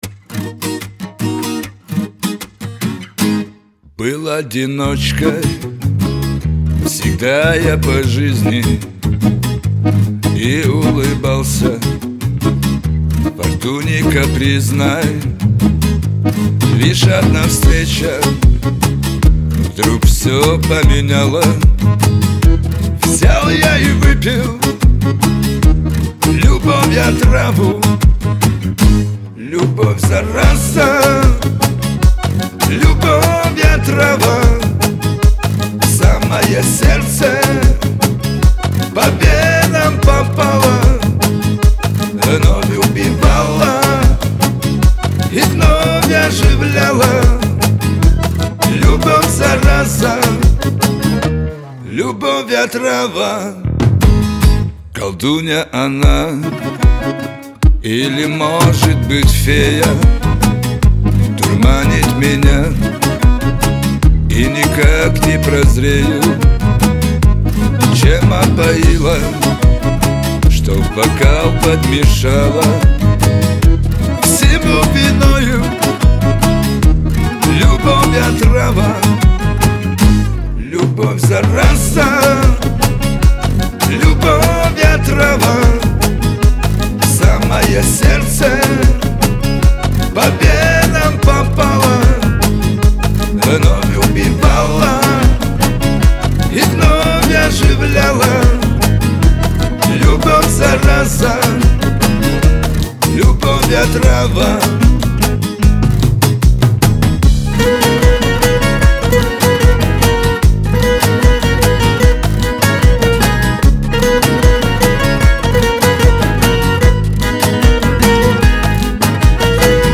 Шансон
грусть